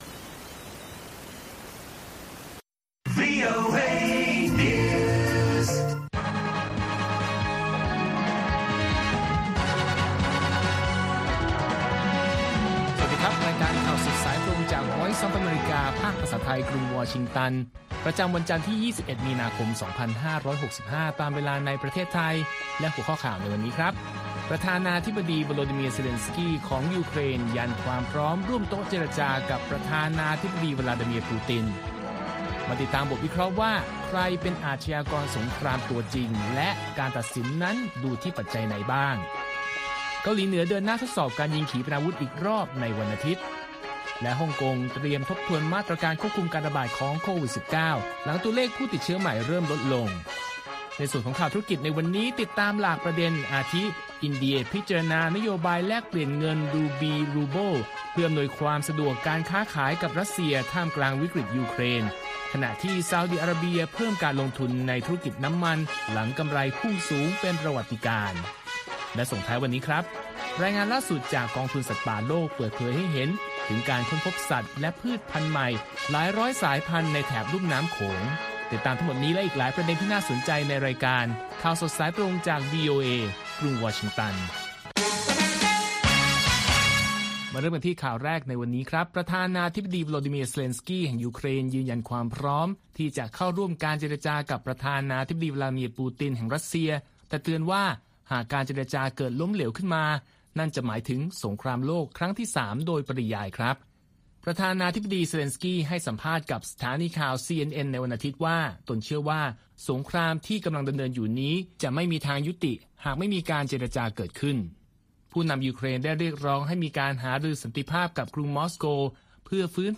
ข่าวสดสายตรงจากวีโอเอ ภาคภาษาไทย 6:30 – 7:00 น. ประจำวันจันทร์ที่ 21 มีนาคม 2565 ตามเวลาในประเทศไทย